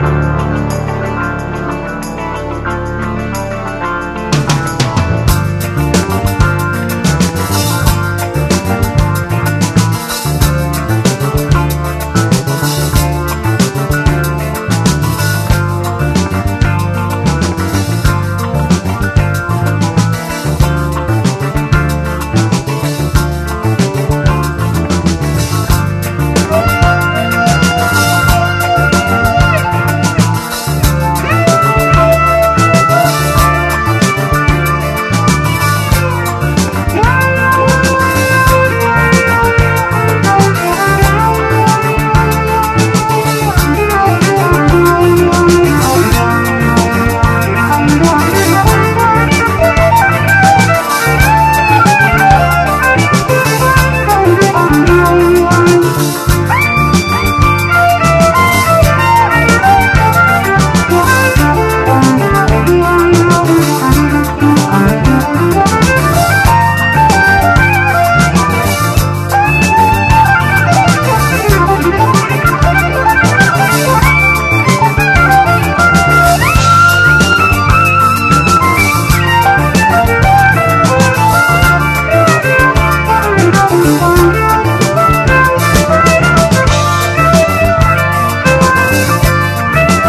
80年の異色シンセ・ディスコ！
マッドでカラフルなコズミック・ディスコ
シンセをフィーチャーのグルーヴィーなクロスオーヴァー